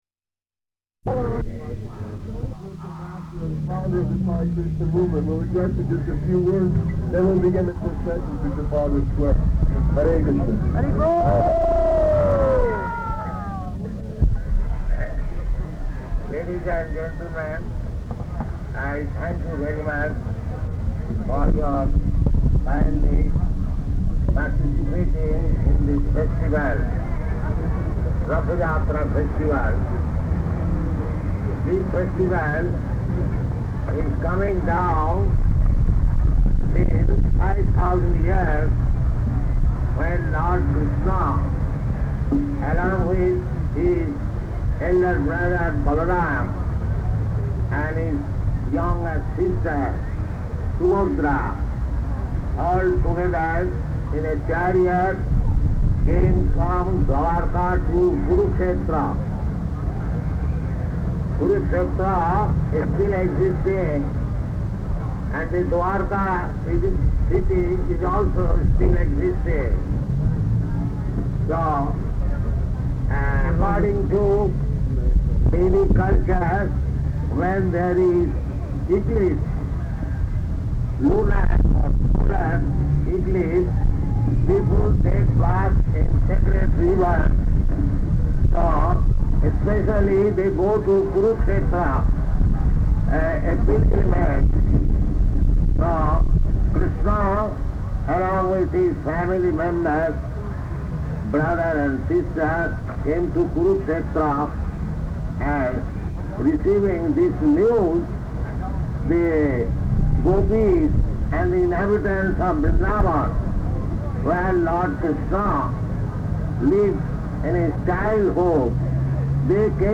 Ratha-yātrā Address
Location: London